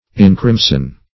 Search Result for " encrimson" : Wordnet 3.0 VERB (1) 1. make crimson ; The Collaborative International Dictionary of English v.0.48: Encrimson \En*crim"son\, v. t. To give a crimson or red color to; to crimson.